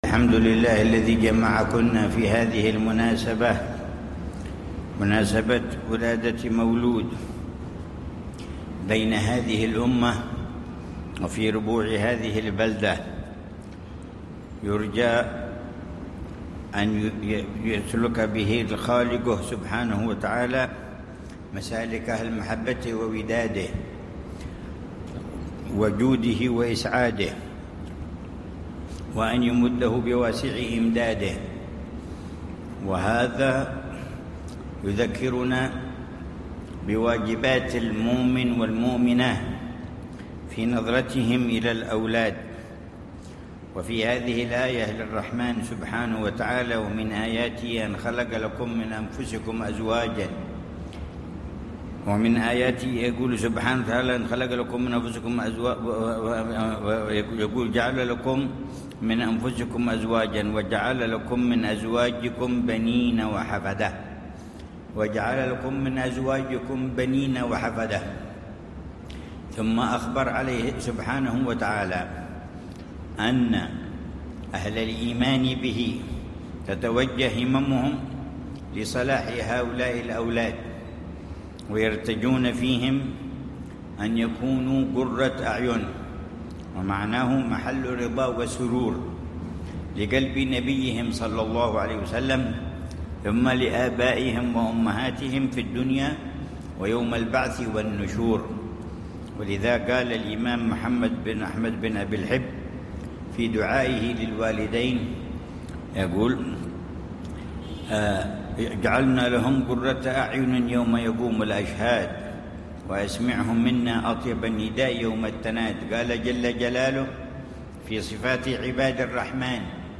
محاضرة العلامة الحبيب عمر بن محمد بن حفيظ للنساء